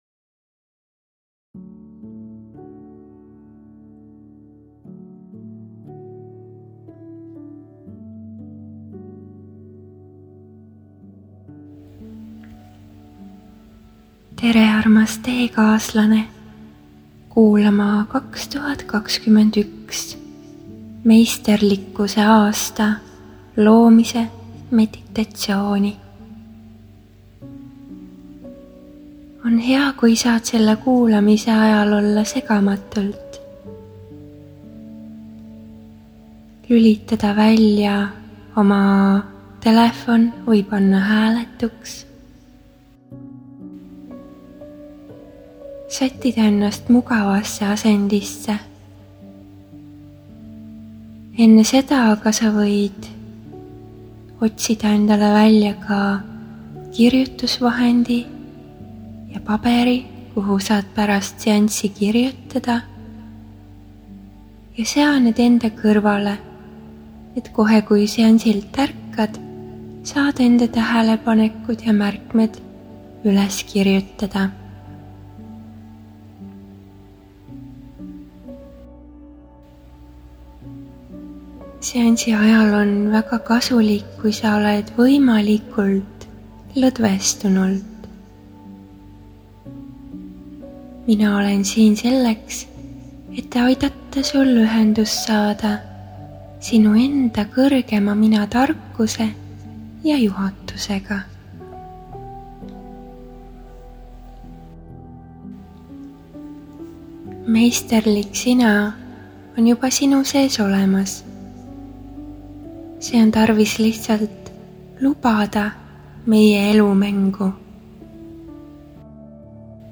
Võid seansi ajal minuga koos taotlusi lausuda või jääda ka lihtsalt rahusse, lõdvestuse ja lubada energiatel läbi enda voolata. Iga kord seda meditatsiooni kuulates saavad puhastatud Su meel, teadvus, keha ja süda.